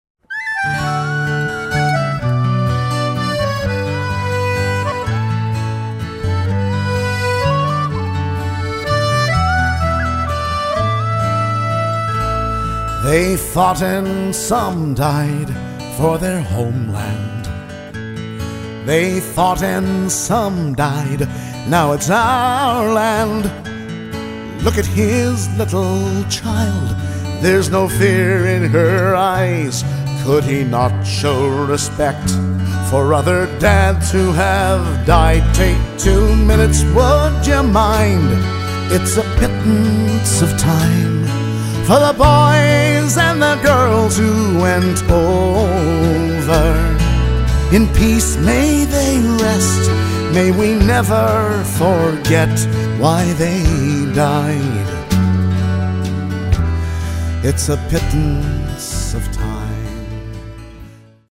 vocals
acoustic guitar
accordion
electric bass
penny whistle
drums